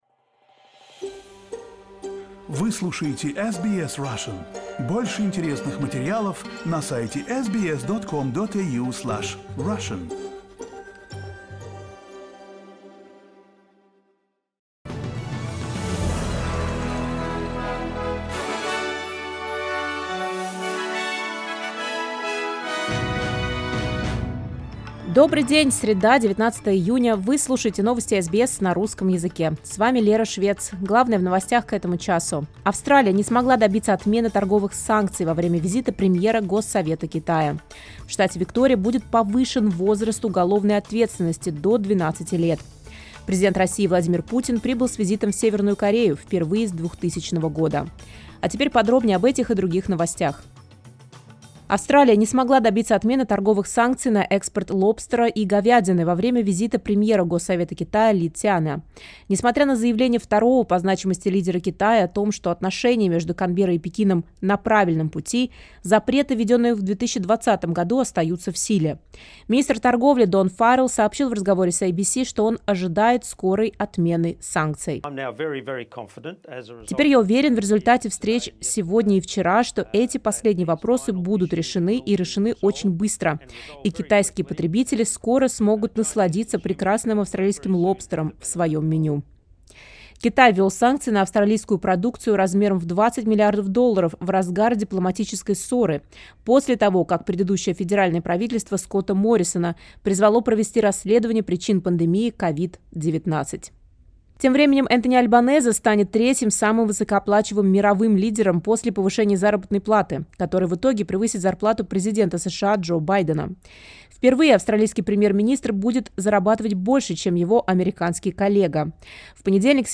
SBS News in Russian — 19.06.2024